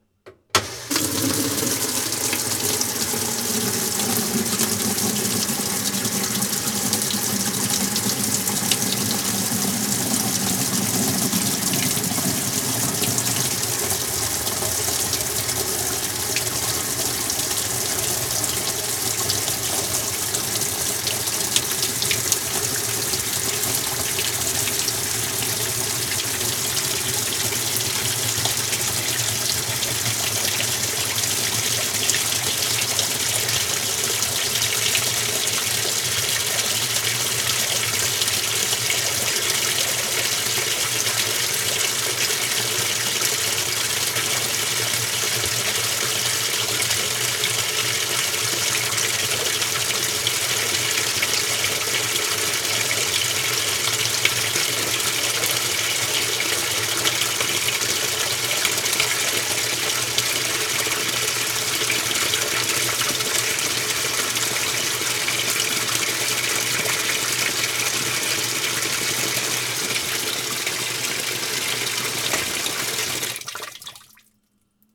household
Washing Machine Fill Cycle